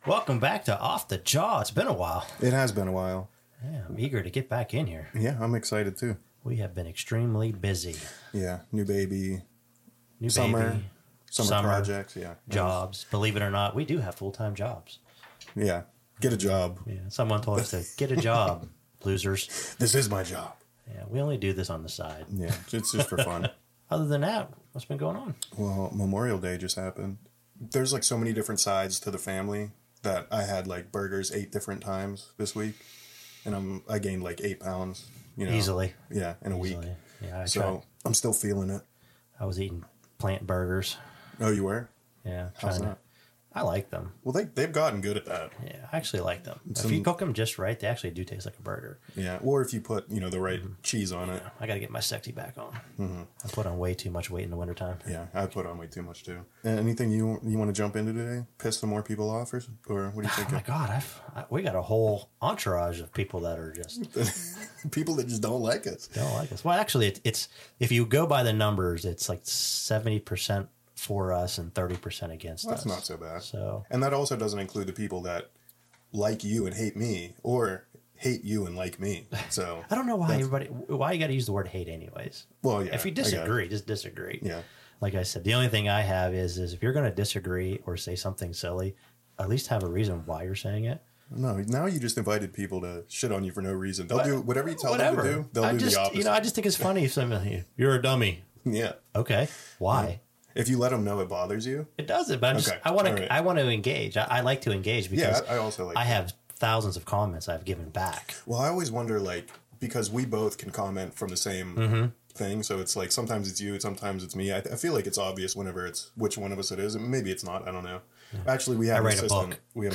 debate